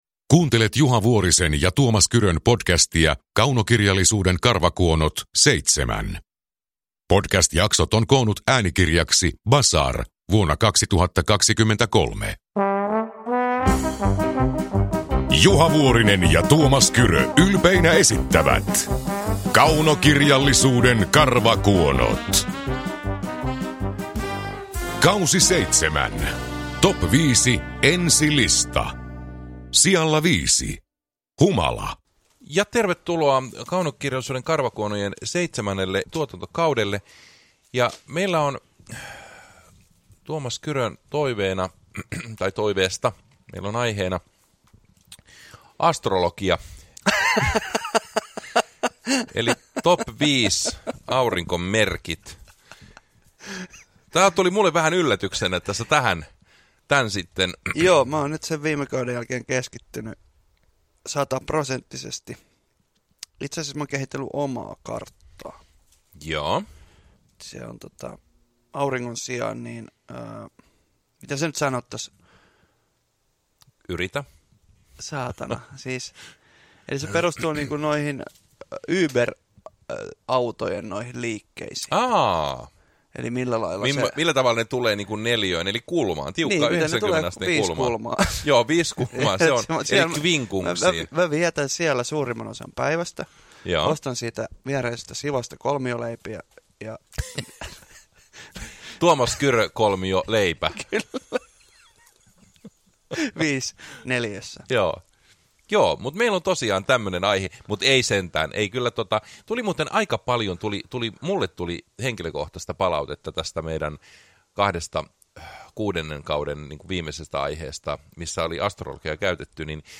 Kaunokirjallisuuden karvakuonot K7 – Ljudbok
Uppläsare: Tuomas Kyrö, Juha Vuorinen